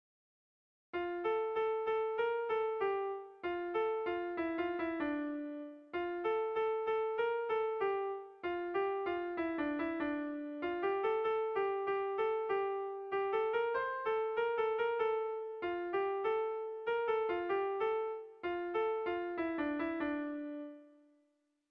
Kontakizunezkoa
Zortziko txikia (hg) / Lau puntuko txikia (ip)
AABA2